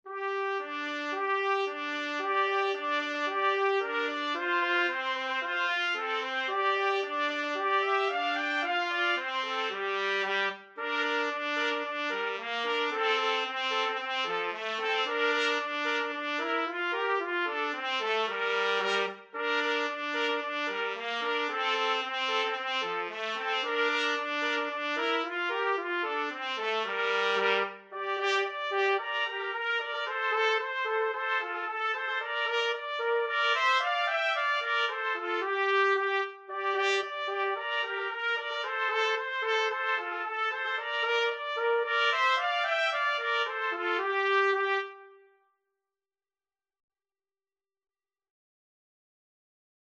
Free Sheet music for Trumpet Duet
"What Shall We do with a Drunken Sailor" is a sea shanty, sung to accompany certain work tasks aboard sailing ships.
2/4 (View more 2/4 Music)
G minor (Sounding Pitch) A minor (Trumpet in Bb) (View more G minor Music for Trumpet Duet )
Allegro moderato = c. 112 (View more music marked Allegro)
Trumpet Duet  (View more Easy Trumpet Duet Music)
drunken_sailor_2TPT.mp3